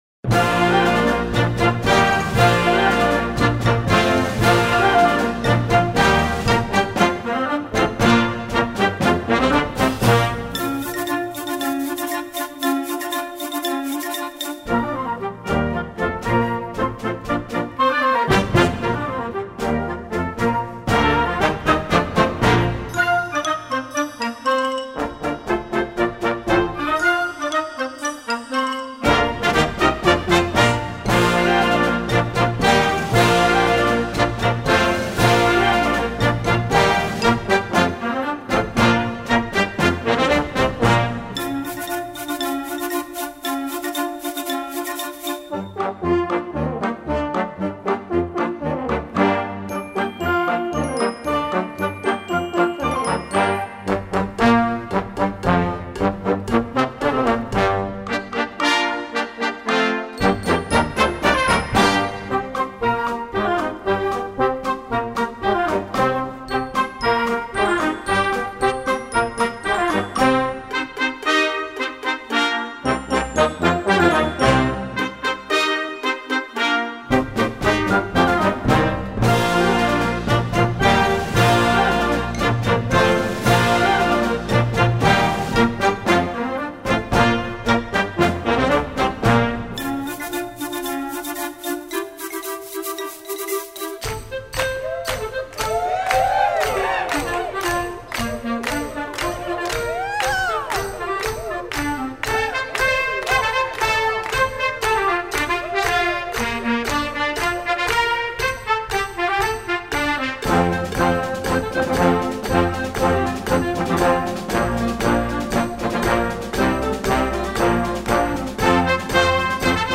Besetzung: Blasorchester
Mit seinen südländischen Themen und dem schnellen Tempo
Mit energiegeladenem, zeitgemäßen Country-Sound